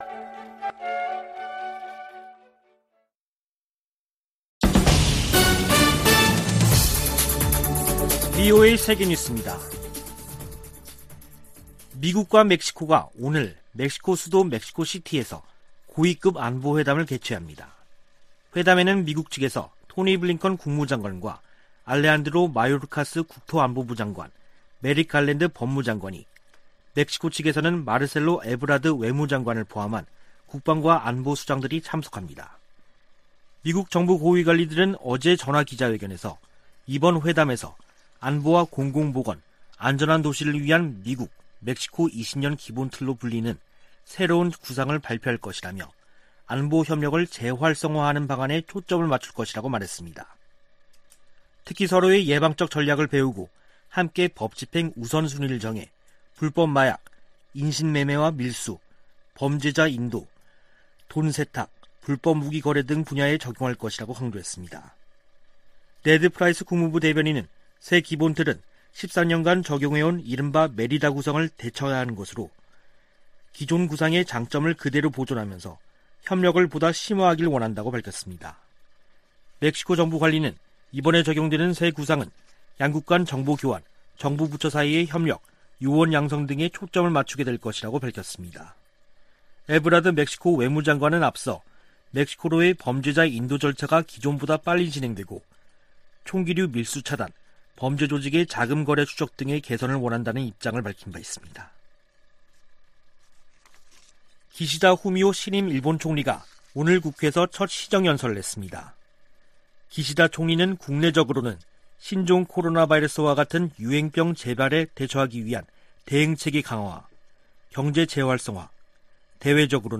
VOA 한국어 간판 뉴스 프로그램 '뉴스 투데이', 2021년 10월 8일 3부 방송입니다. 코로나 방역 지원 물품이 북한에 도착해, 남포항에서 격리 중이라고 세계보건기구(WHO)가 밝혔습니다. 대북 인도적 지원은 정치 상황과 별개 사안이라고 미 국무부가 강조했습니다. 북한에서 장기적인 코로나 대응 규제 조치로 인권 상황이 더 나빠졌다고 유엔 북한인권 특별보고관이 총회에 제출한 보고서에 명시했습니다.